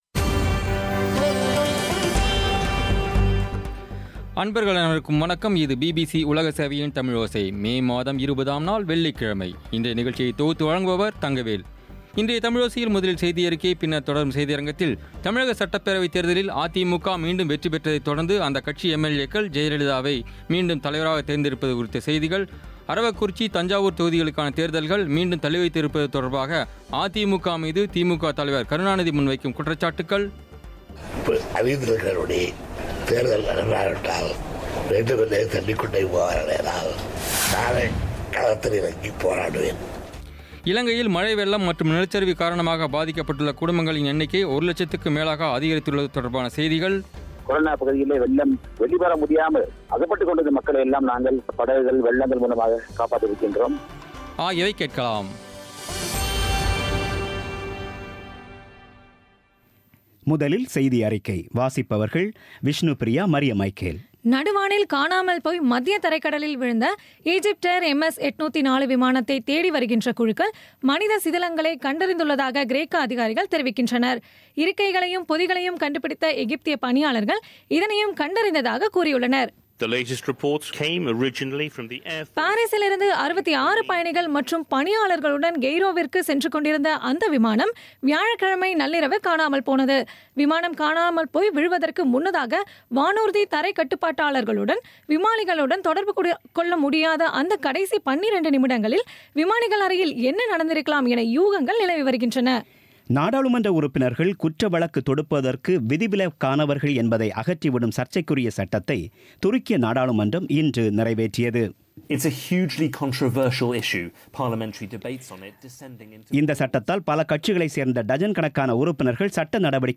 இன்றைய தமிழோசையில், முதலில் செய்தியறிக்கை, பின்னர் தொடரும் செய்தியரங்கில்